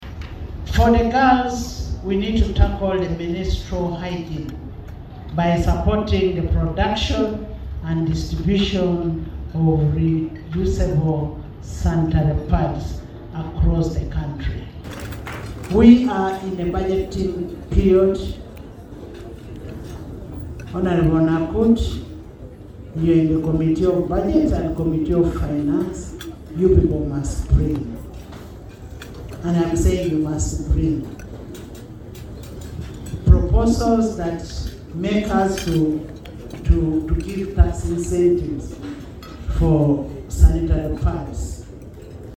This call was made at Parliament on Friday, 06 March 2026 during the Parliamentary Women’s Day breakfast meeting in commemoration of this year’s International Women’s Day.